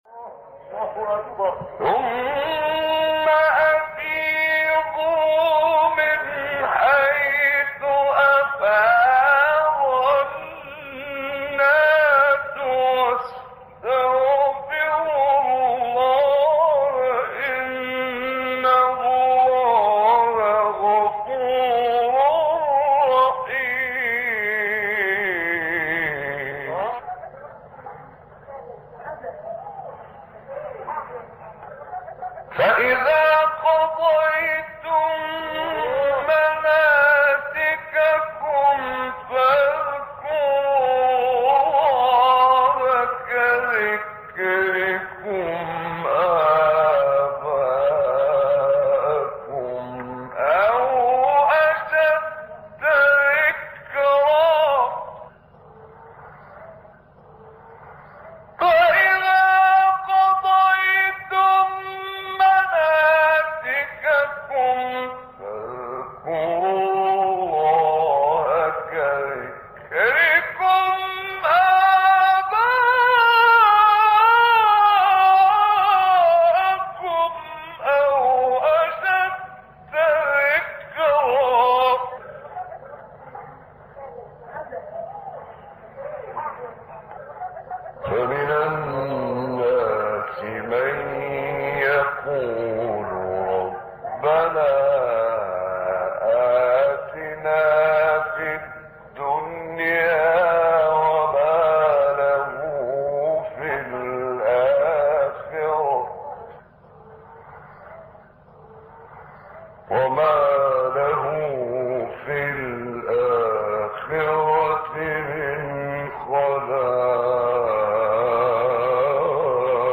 سوره : بقره آیه: 199-203 استاد : محمد عمران مقام : مرکب خوانی(سه گاه * چهارگاه * حجاز * بیات) قبلی بعدی